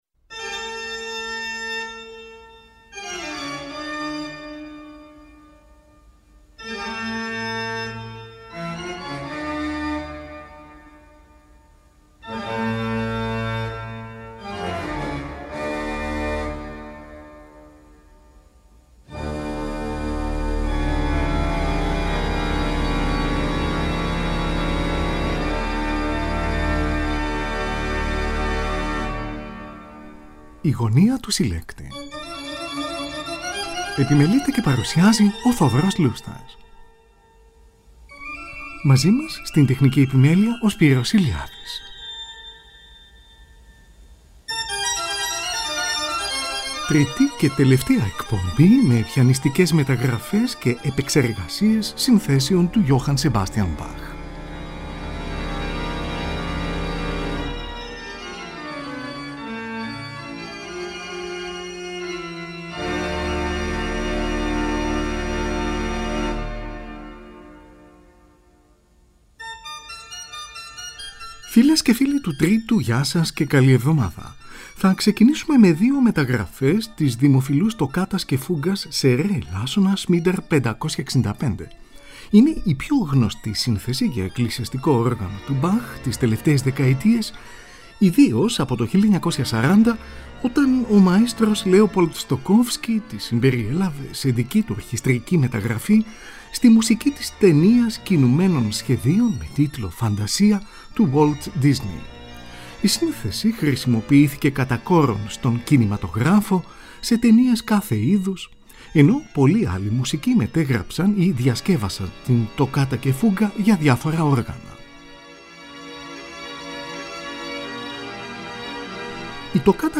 TΡΙΤΗ ΚΑΙ ΤΕΛΕΥΤΑΙΑ ΕΚΠΟΜΠΗ ΜΕ ΠΙΑΝΙΣΤΙΚΕΣ ΜΕΤΑΓΡΑΦΕΣ ΚΑΙ ΕΠΕΞΕΡΓΑΣΙΕΣ ΣΥΝΘΕΣΕΩΝ ΤΟΥ JOHANN SEBASTIAN BACH
Τοκάτα και φούγκα για εκκλησιαστικό όργανο, σε ρε ελάσσονα, BWV 565, εις διπλούν: